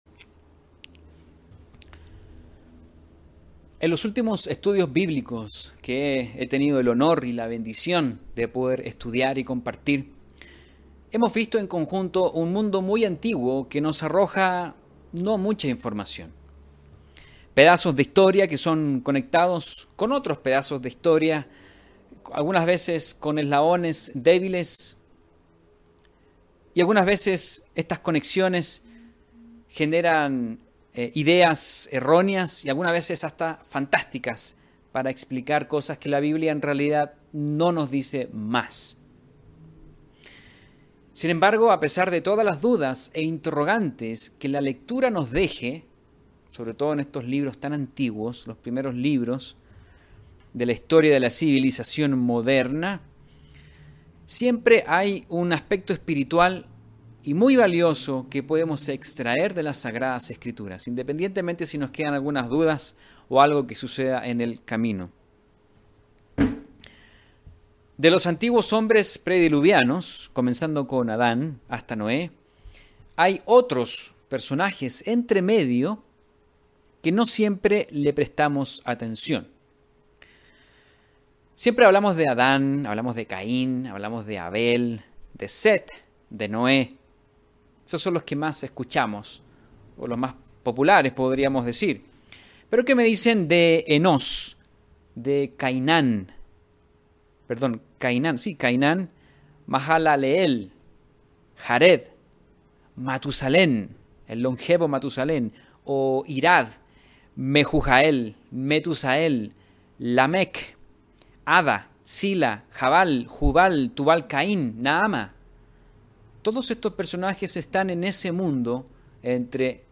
La fe de Enoc y su obediencia, junto con su interesante genealogía pueden dejarnos valiosas lecciones a aplicar en nuestra vida personal. Mensaje entregado el 25 de julio de 2020.